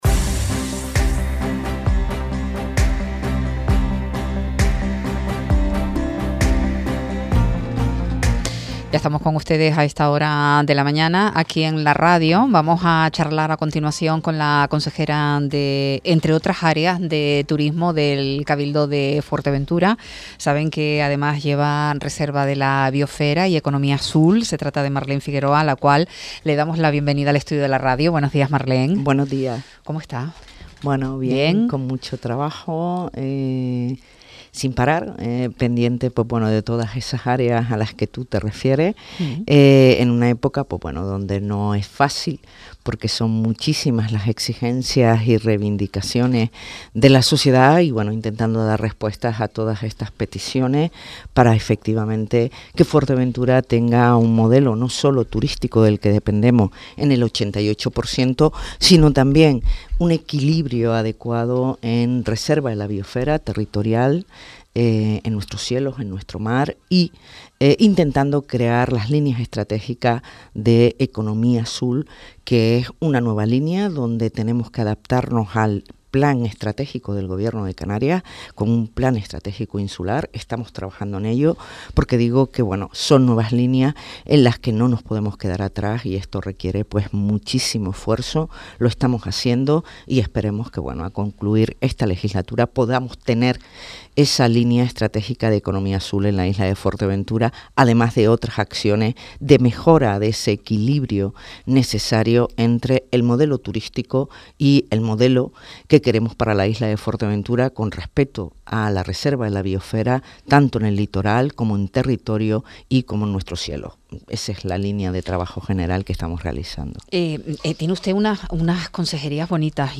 Marlen Figueroa, consejera de Turismo, Economía Azul y Reserva de La Biosfera recordó en Radio Sintonía que el El Plan de Sostenibilidad Turística en Destino invierte en estos momentos en Fuerteventura cerca de 10 millones de euros. El plan, como recordó Figueroa, lo impulsa el Gobierno de España con fondos de la Unión Europea.
Entrevistas